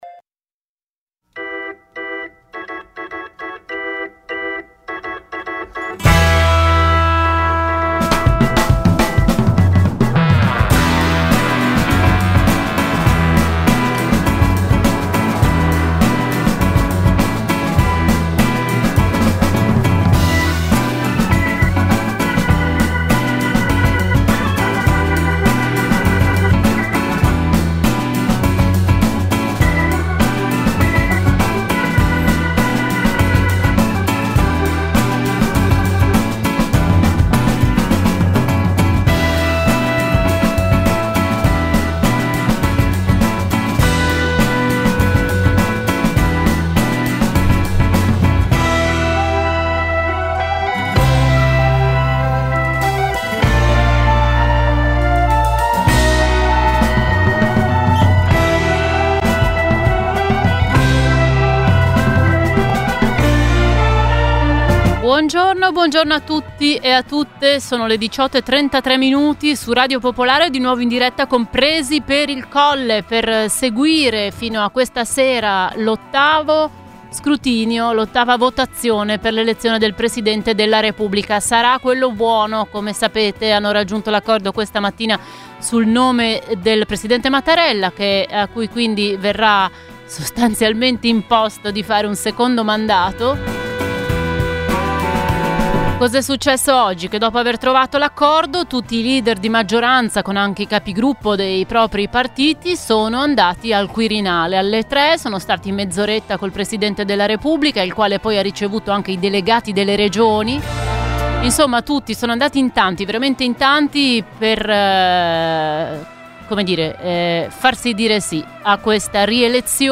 Gli inviati e gli ospiti, le notizie e le vostre opinioni.